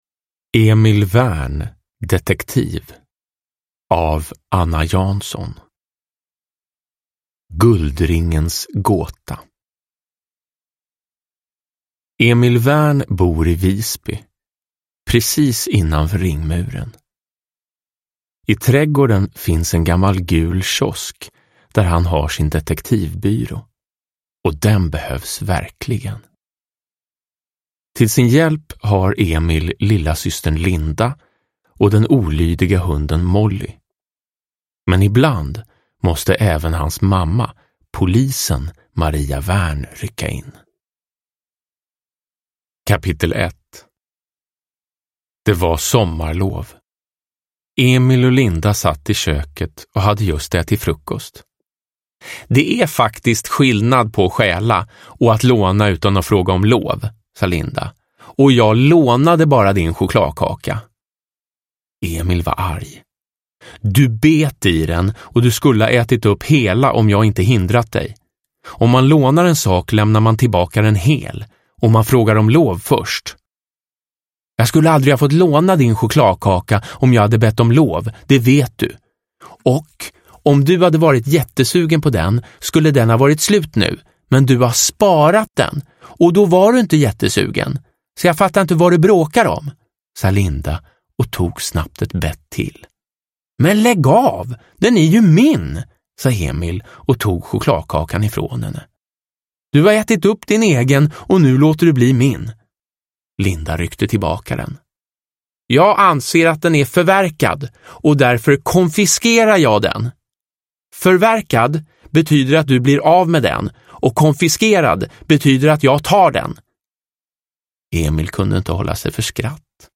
Produkttyp: Digitala böcker
Uppläsare: Jonas Karlsson